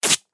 7. Резкое отклеивание липкой ленты